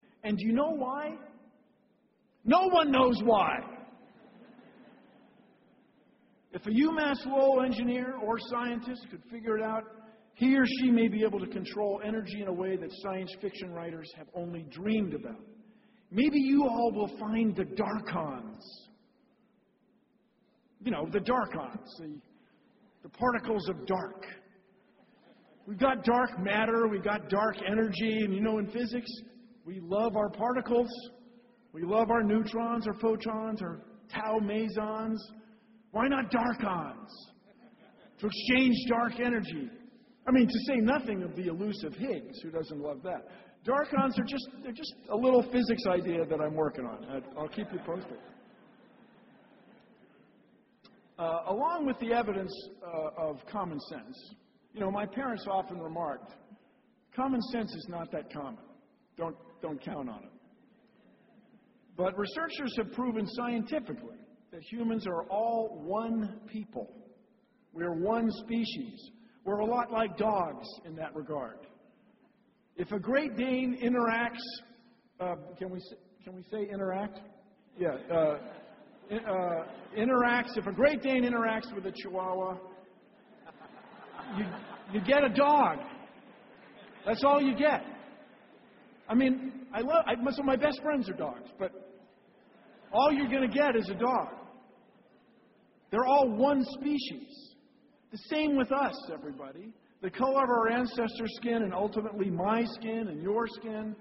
公众人物毕业演讲 第167期:比尔·奈马萨诸塞大学2014(14) 听力文件下载—在线英语听力室